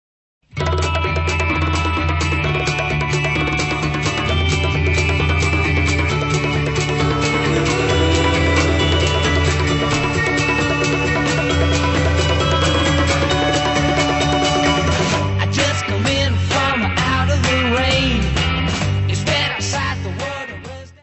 Área:  Pop / Rock
Stereo